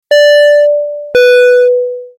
Category: SFX Ringtones